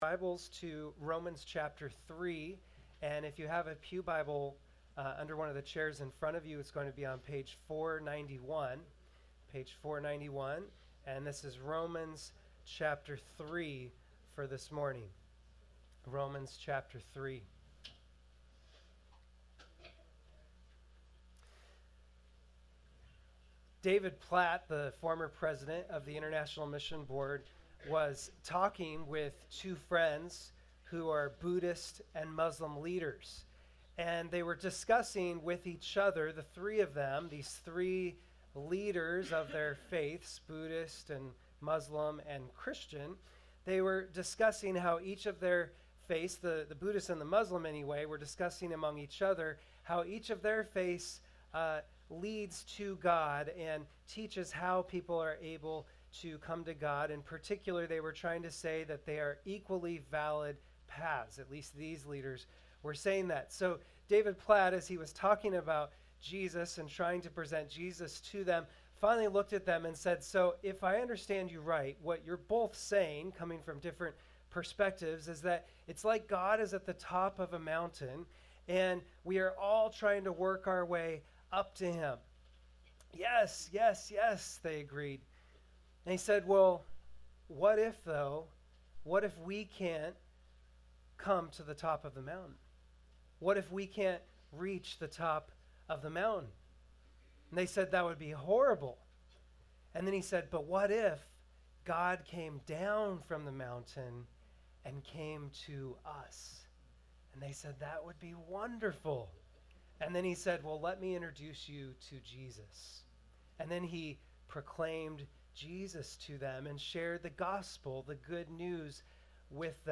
Christ (#3 of 5 in the “Good News” Sermon Series) – Northshire Baptist Church